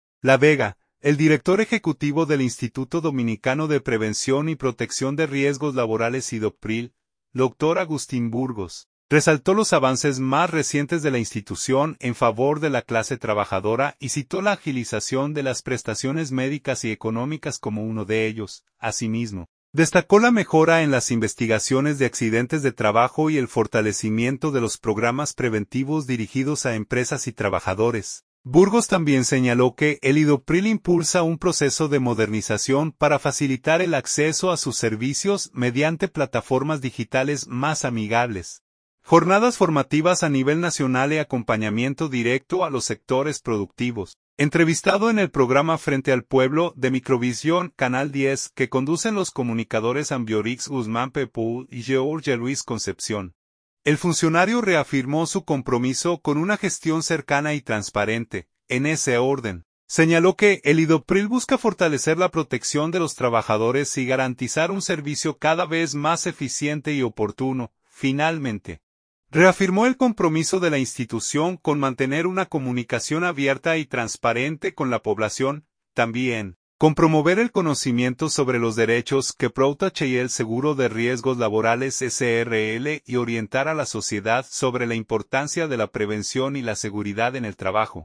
La Vega. — El director ejecutivo del Instituto Dominicano de Prevención y Protección de Riesgos Laborales (Idoppril), doctor Agustín Burgos, resaltó los avances más recientes de la institución en favor de la clase trabajadora y citó la agilización de las prestaciones médicas y económicas como uno de ellos.